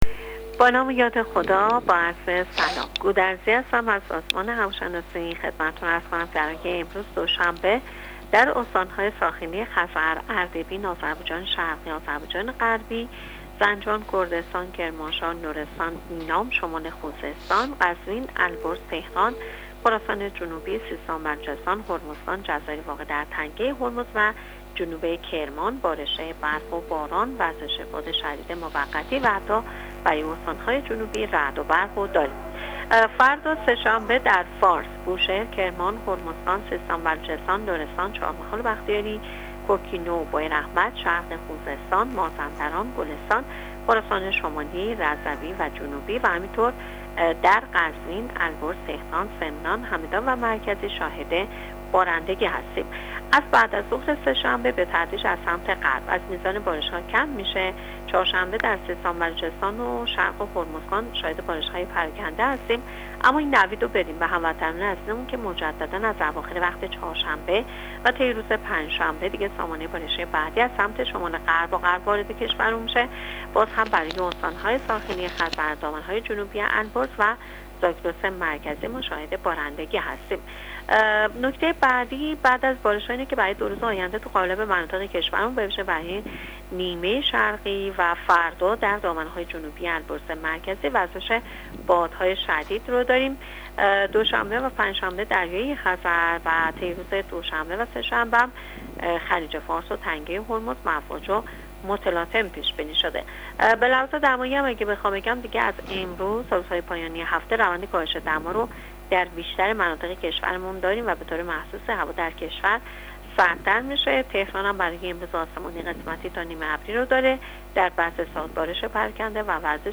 کارشناس سازمان هواشناسی کشور در گفت‌وگو با رادیو اینترنتی پایگاه خبری وزارت راه‌ و شهرسازی، آخرین وضعیت آب‌و‌هوای کشور را تشریح کرد.
گزارش رادیو اینترنتی پایگاه خبری از آخرین وضعیت آب‌‌و‌‌‌هوای بیست و هفتم دی: